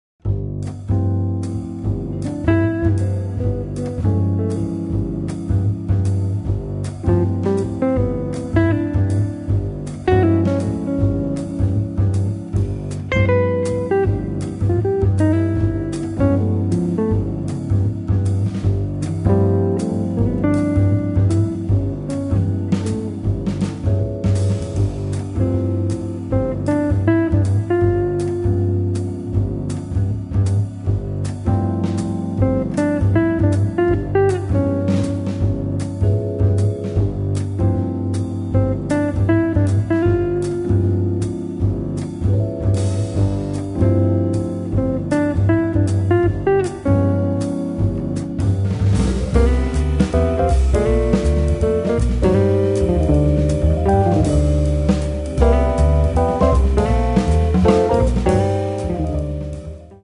chitarra
organo e piano elettrico
contrabbasso
batteria